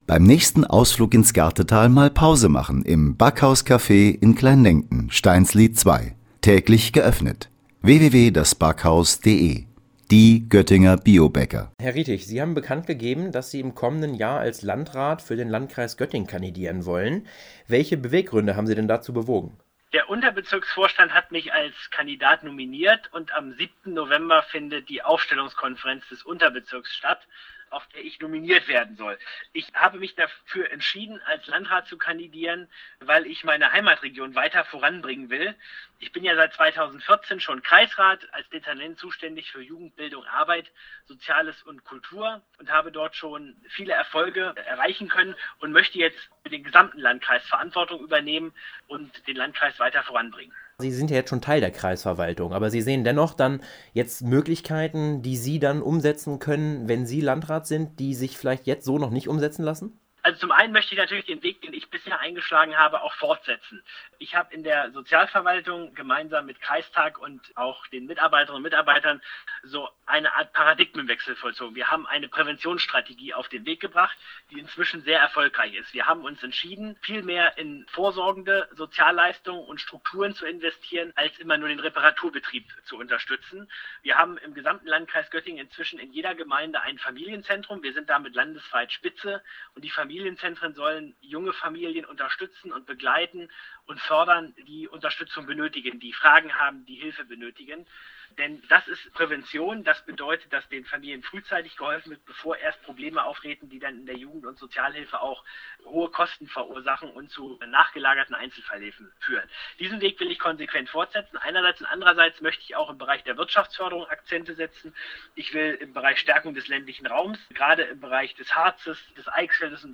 Exklusiv-Interview